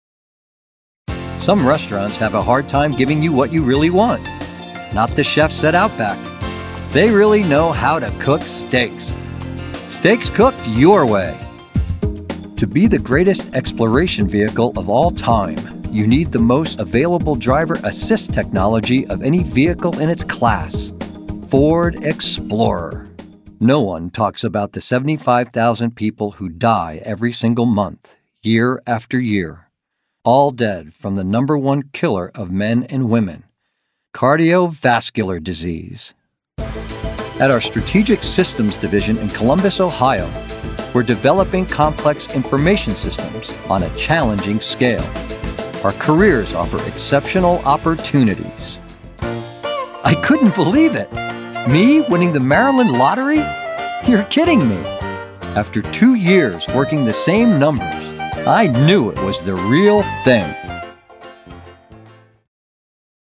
Commercial
English - Midwestern U.S. English
Middle Aged
Senior